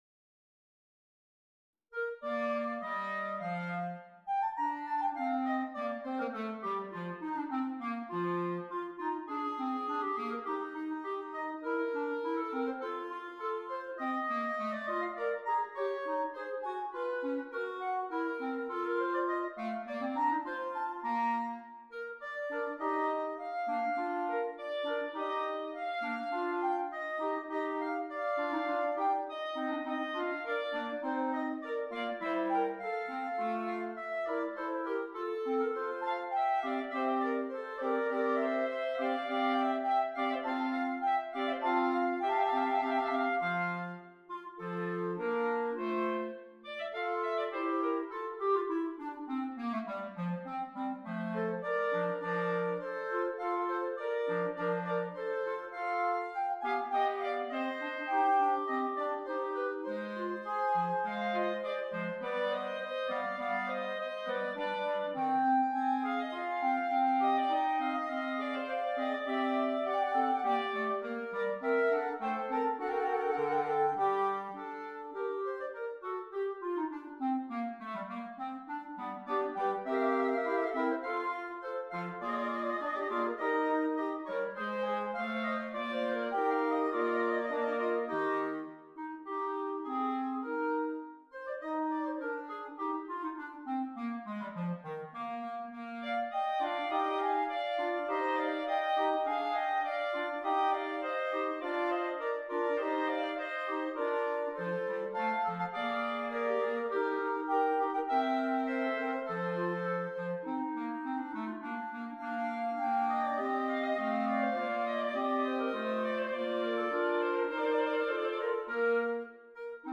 4 Clarinets
arranged here for 4 clarinets